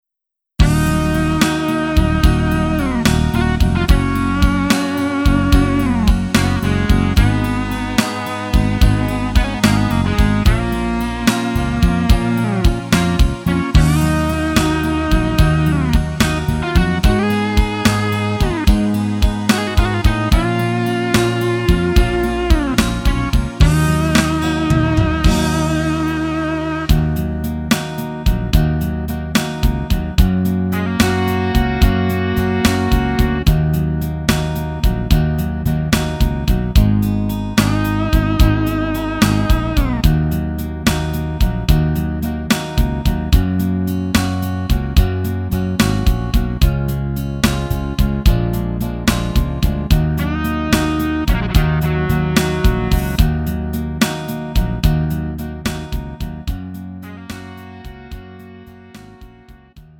음정 -1키 3:53
장르 구분 Lite MR